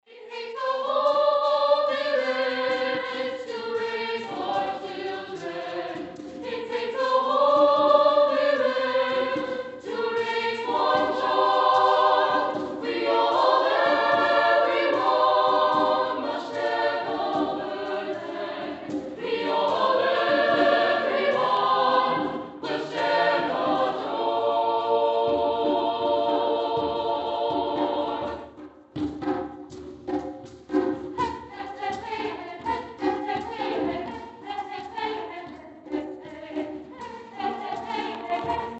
For the first time in three years, First United Methodist Church’s halls were alive with melodic selections from multiple choirs Monday night in support of Emporia’s Habitat for Humanity.
habitat-music-1.mp3